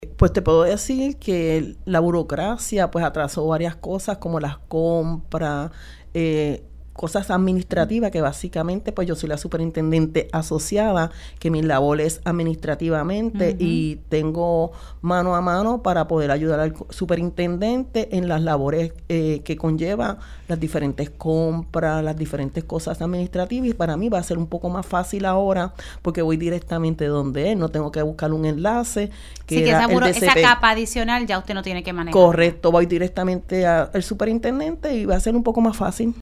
Mientras, la superintendente asociada, Diana Crispín, destacó que el que el NPPR fuera parte del DSP dificultaba algunos procesos debido a la burocracia existente, por lo que ahora resultará más fácil concretar sus labores.